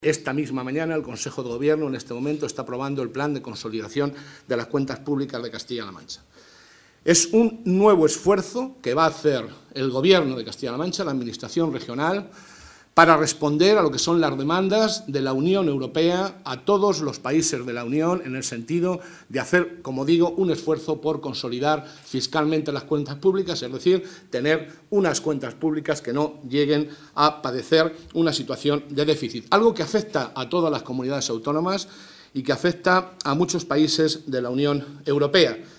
El portavoz del Grupo Parlamentario Socialista, José Molina, ha destacado, en una rueda de prensa en Ciudad Real, que el Gobierno de Castilla-La Mancha acometerá un “esfuerzo importante” a través del Plan de Consolidación de las Cuentas Públicas, aprobado esta misma mañana por el Consejo de Gobierno.